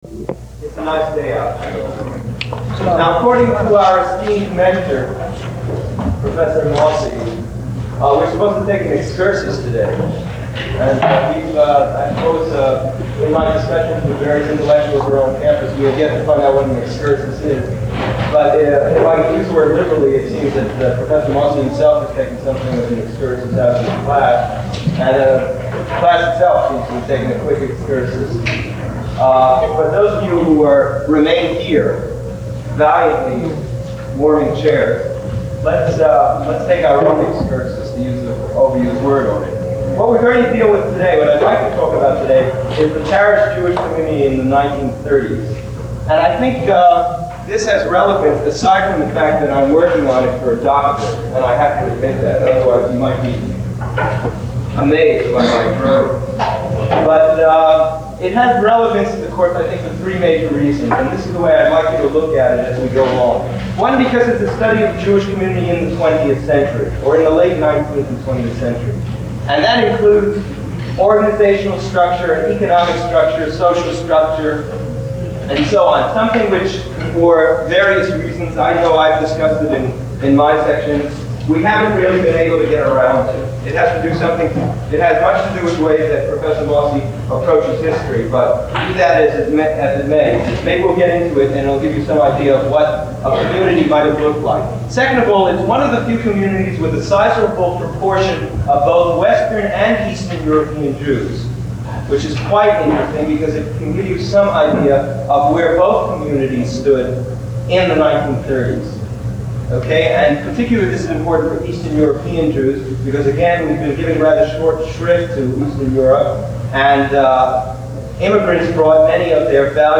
Lecture #20 - April 7, 1971